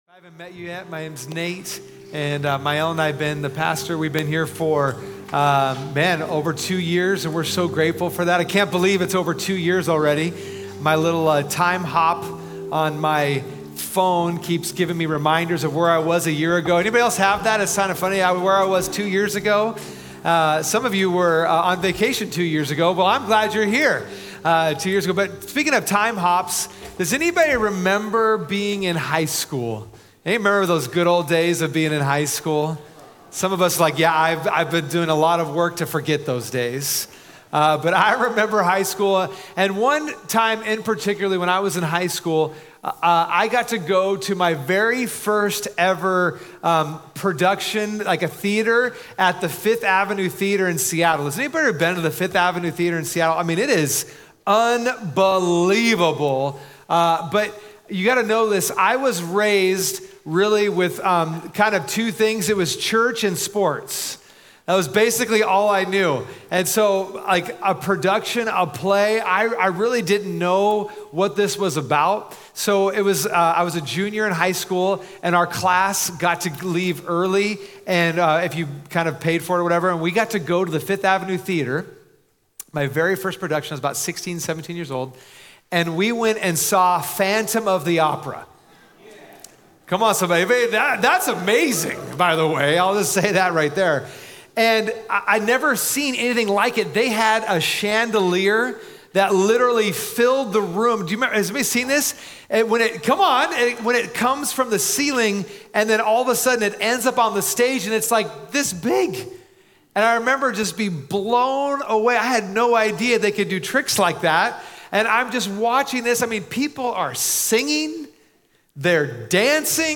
Sunday Messages from Portland Christian Center "How Does It End?"